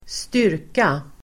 Uttal: [²st'yr:ka]